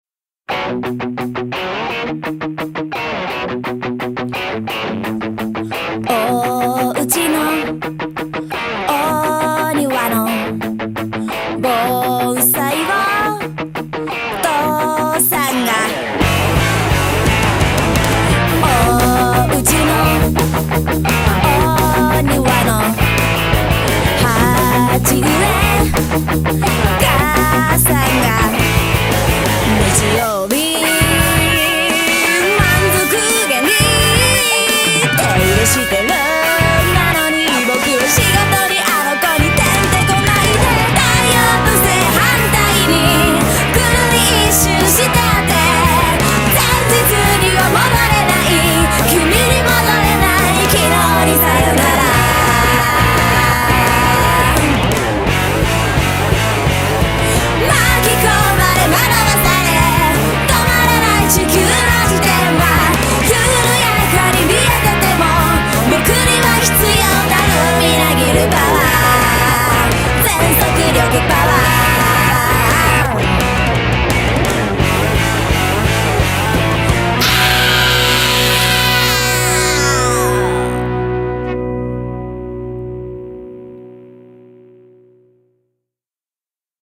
BPM172
Genre: PUNK ROCK